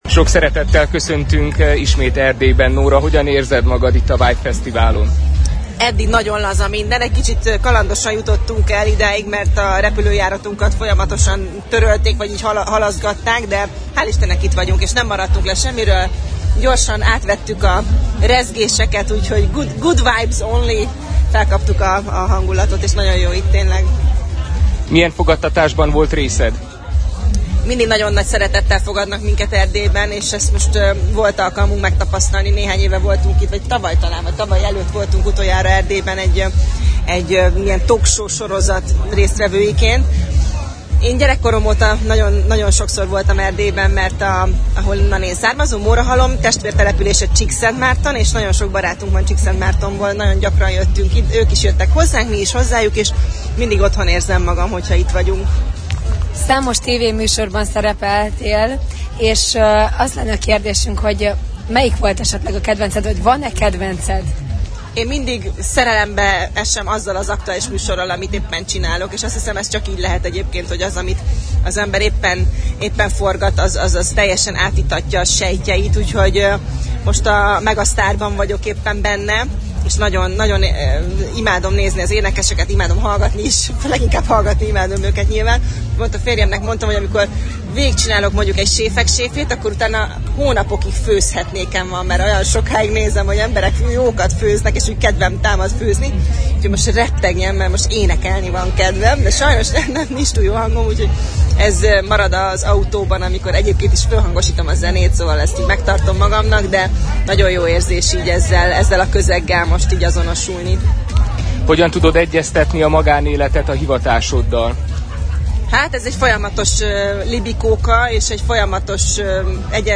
kulisszatitkokról kérdezte a műsorvezetőt